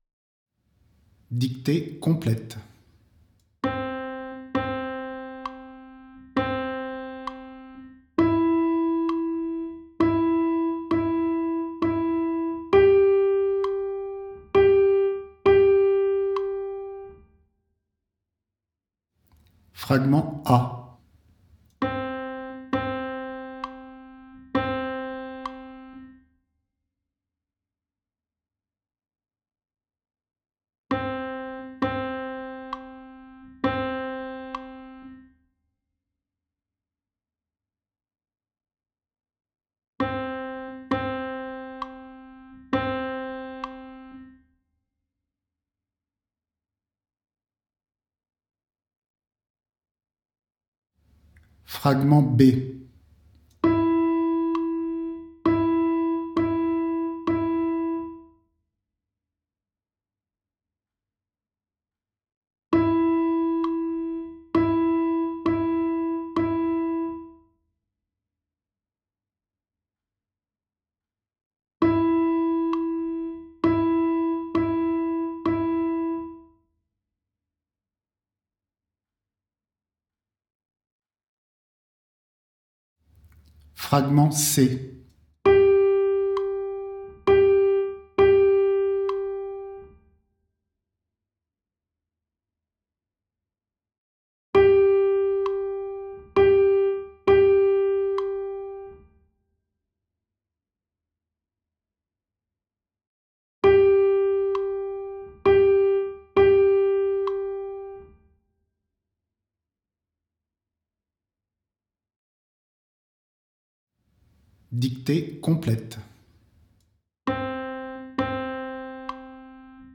Dictées de rythmes